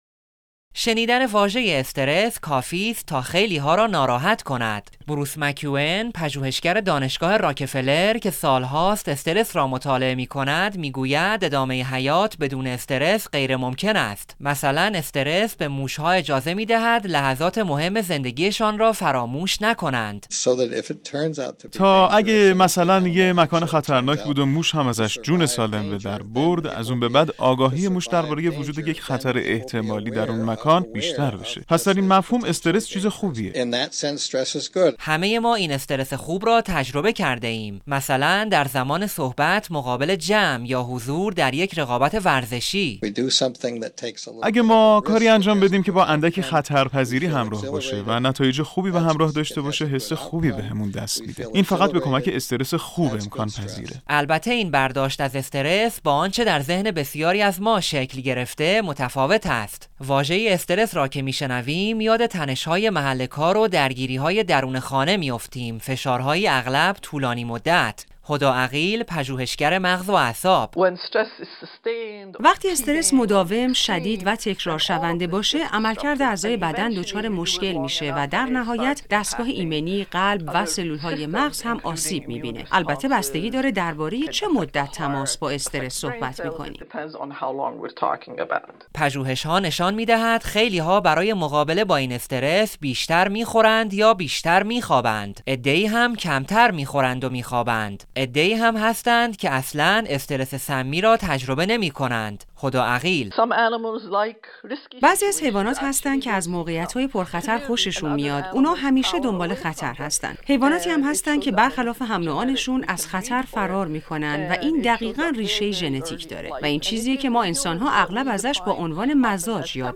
در این گزارش شنیدنی نگاهی داریم به پژوهشهای انجام شده درباره "استرس خوب" و "استرس سمی."